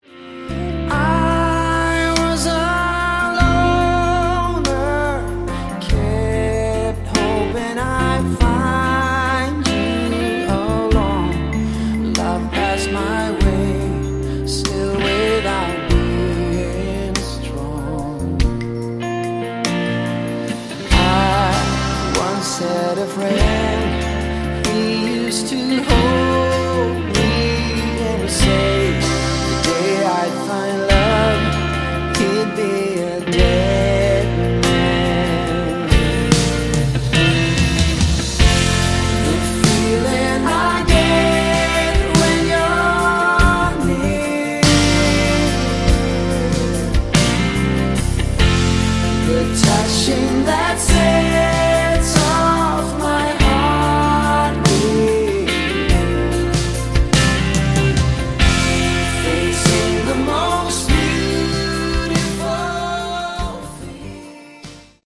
Category: Westcoast AOR
lead vocals